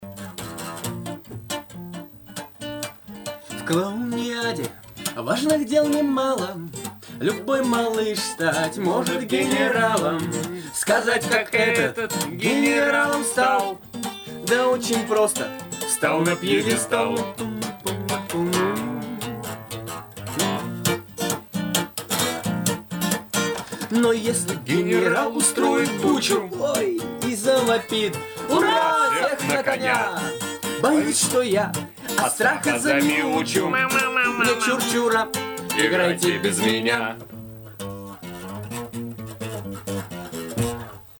Треки, исполненные вживую в Доме радио: